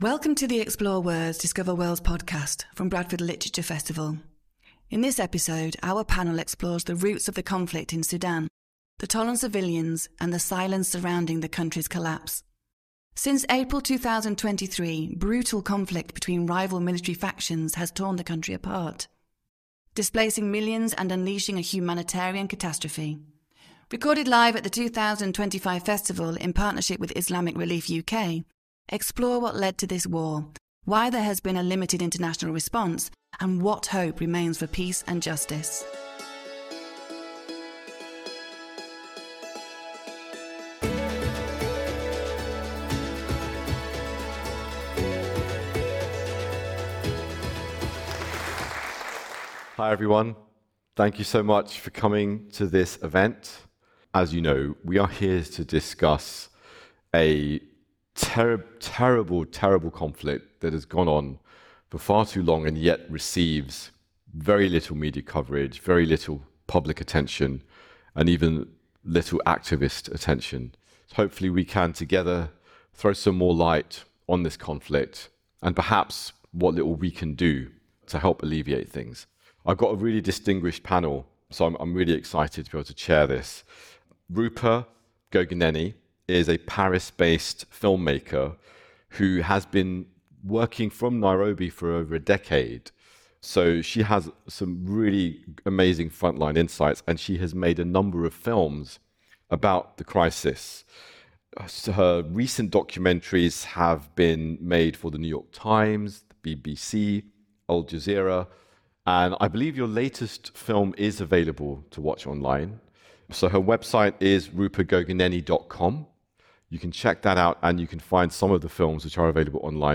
Since April 2023, brutal conflict between rival military factions has torn the country apart, displacing millions and unleashing a humanitarian catastrophe. In this urgent and illuminating panel, we explore the roots of the conflict, the toll on civilians, and the silence surrounding Sudan’s collapse.